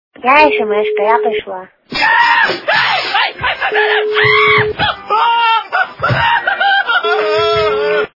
- звуки для СМС
При прослушивании Звонок для СМС - Я СМС-ка, я пришла и истерический смех качество понижено и присутствуют гудки.
Звук Звонок для СМС - Я СМС-ка, я пришла и истерический смех